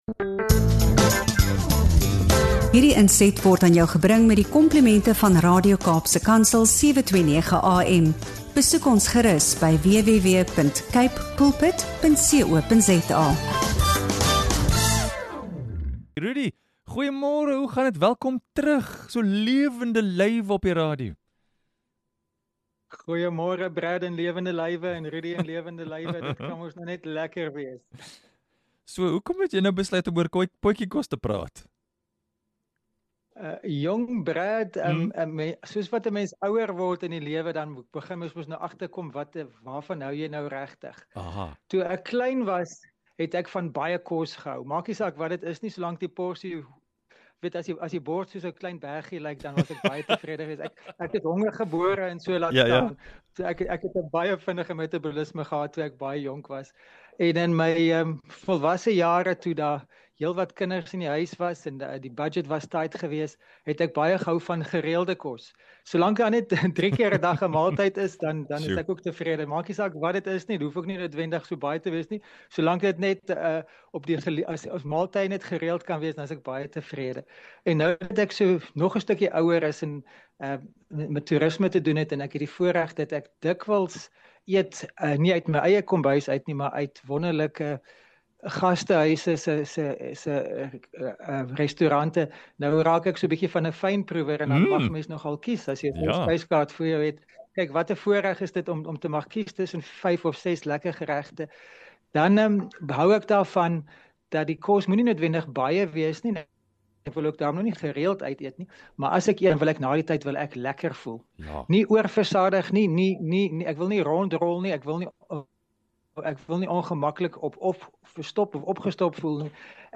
ontbytgesprek